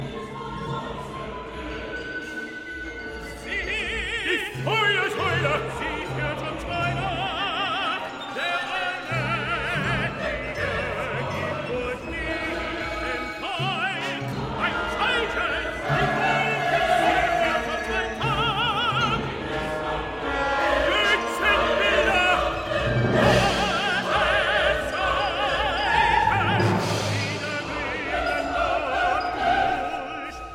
the chorus has entered